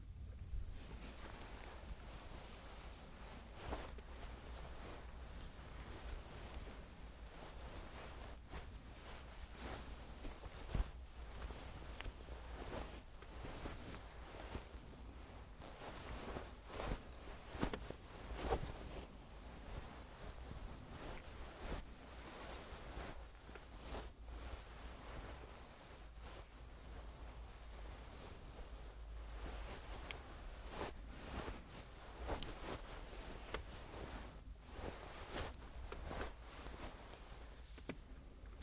Звуки ваты
Хрустящие, шуршащие и мягкие аудиофайлы помогут расслабиться, создать атмосферу или использовать их в своих проектах.
Звук передвигаем вату